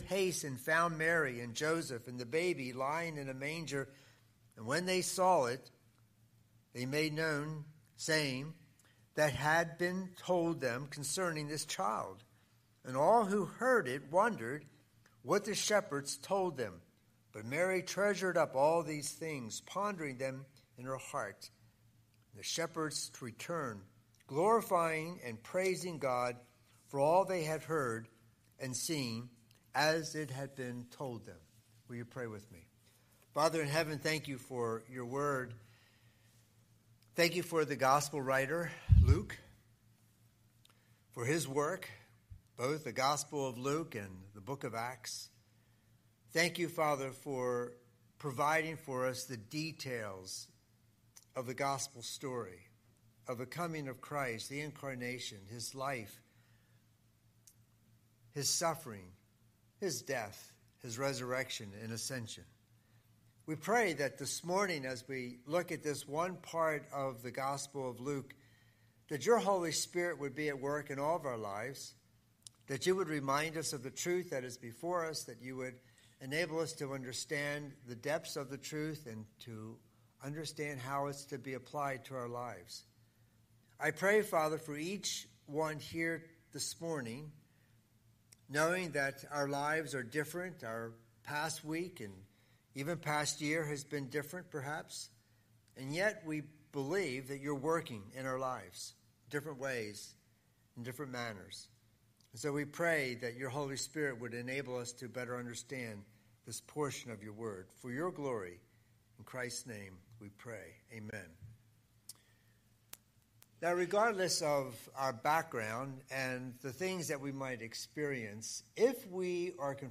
Scripture: Luke 2:8–20 Series: Sunday Sermon